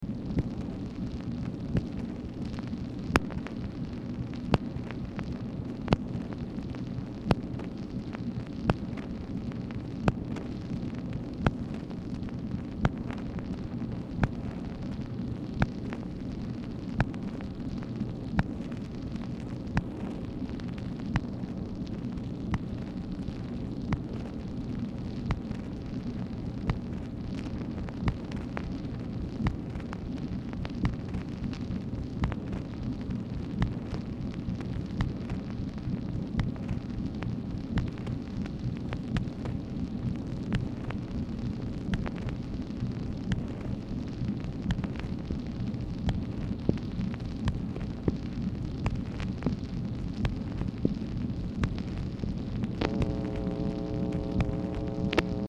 MACHINE NOISE
Dictation belt